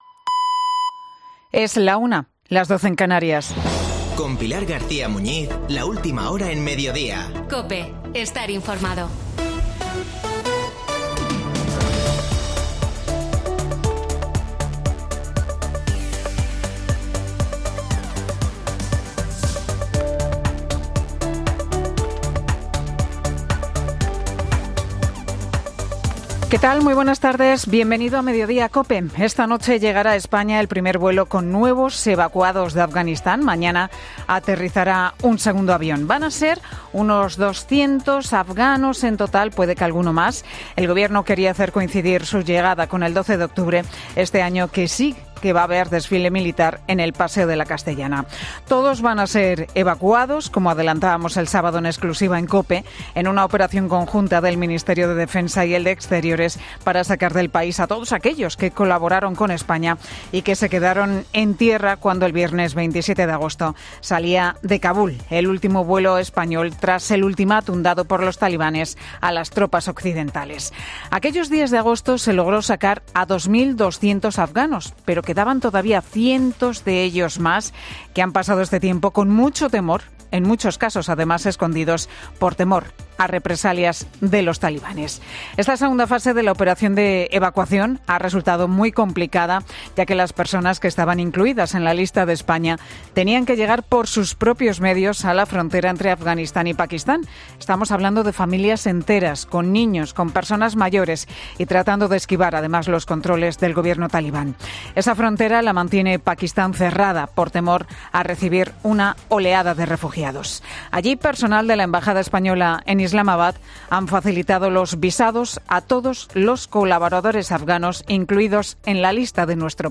El Monólogo de Pilar García muñiz en 'Mediodía COPE'